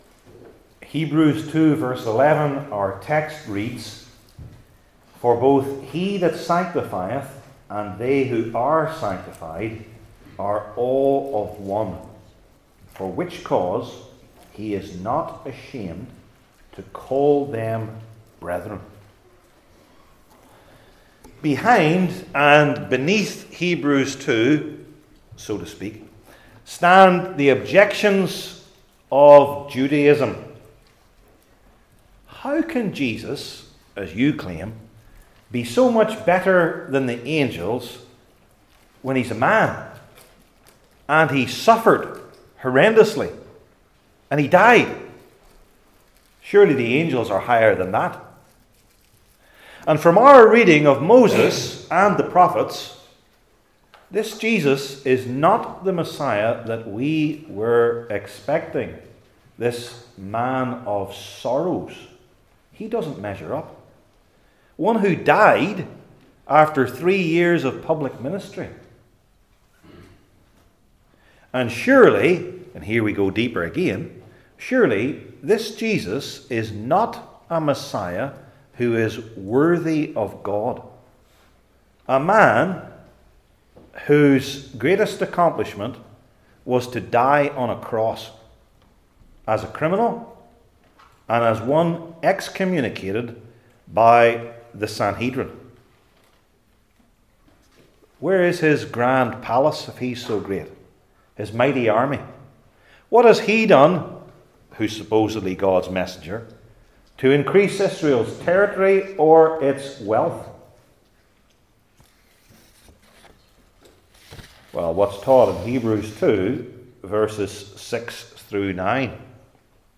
New Testament Sermon Series